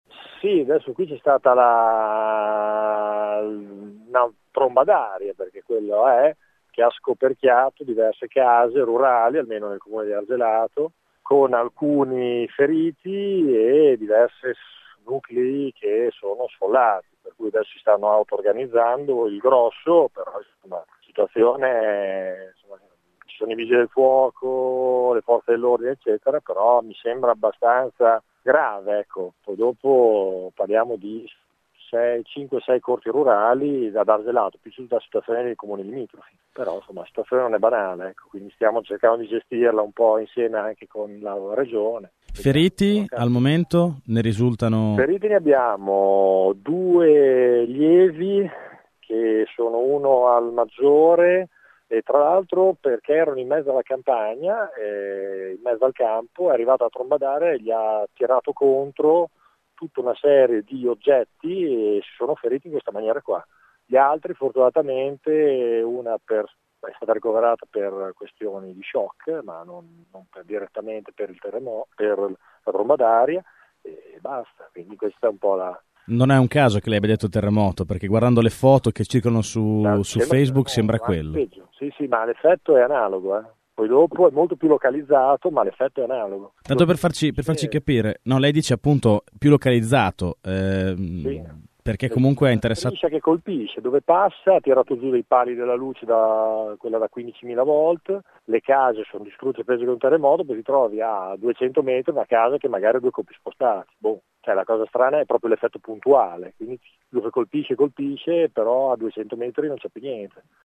Due di loro, come racconta ai nostri microfoni il sindaco Andrea Tolomelli, sono stati feriti dagli oggetti sollevati dalla tromba d’aria.
Ascolta Tolomelli
Andrea-Tolomelli.mp3